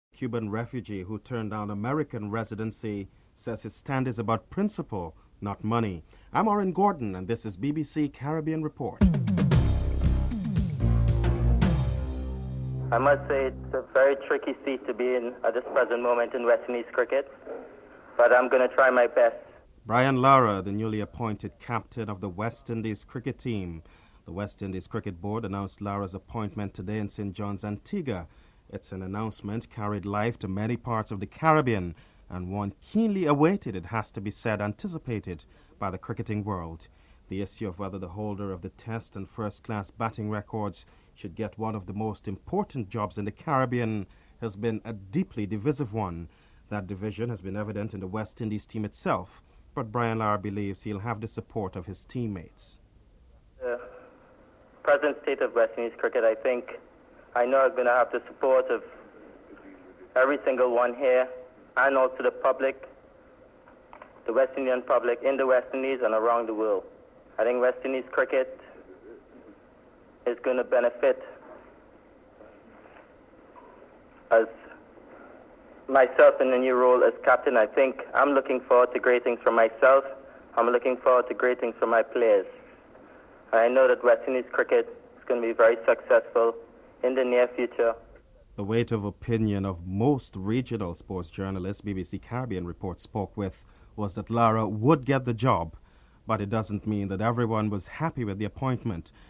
The report concludes with Andy Roberts giving his reaction to Brian Lara's appointment as captain.